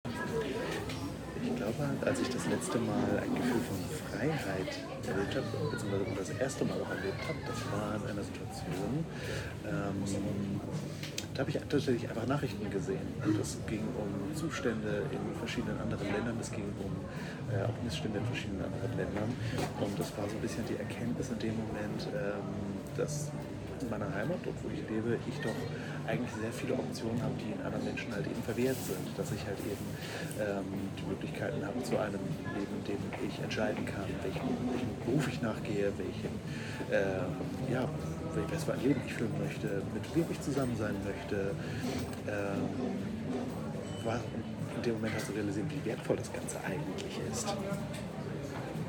Standort der Erzählbox:
Bürgerfest Schwerin im Rahmen des Projektes